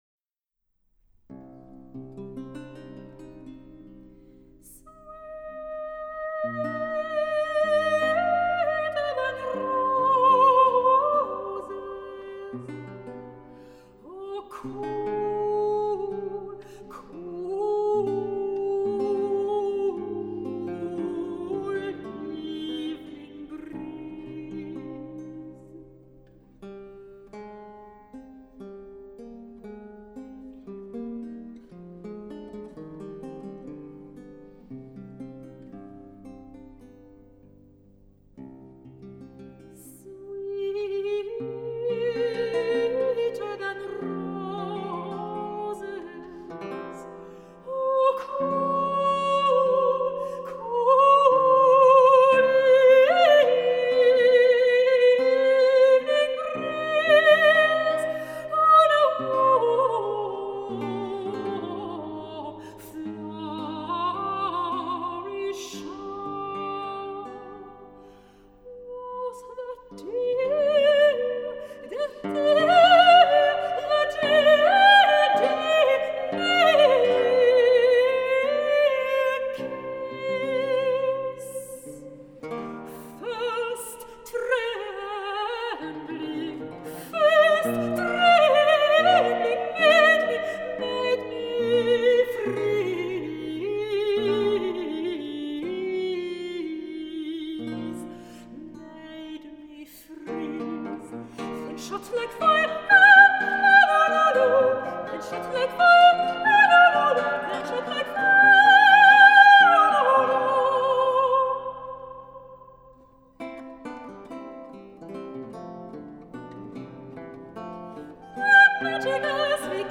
Sopran
Theorbe